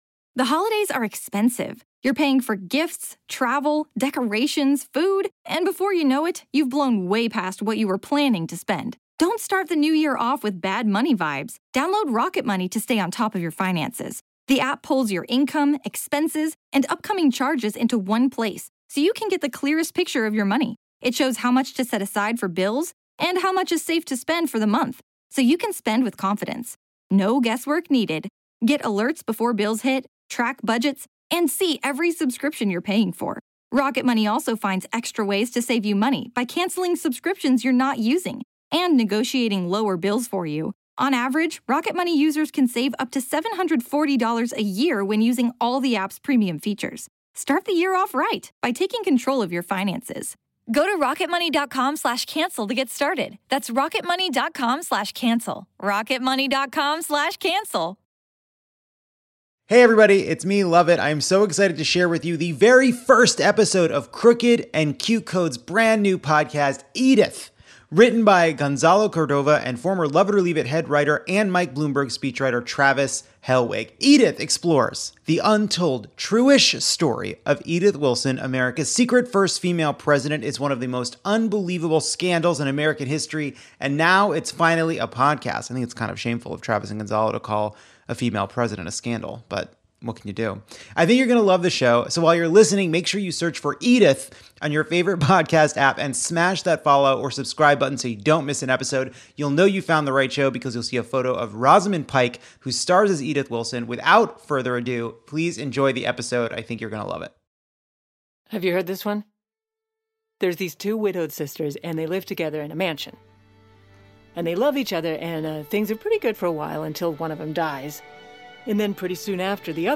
"Edith!" starring Golden Globe winner and Academy Award nominee Rosamund Pike, is a scripted comedy podcast about the untold true-ish story of Edith Wilson, America's secret first female president. For almost a year after Woodrow Wilson's stroke, Edith issued orders as her husband.
Now, finally, it is a satirical, narrative podcast.